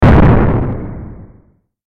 bomber_ex.2.ogg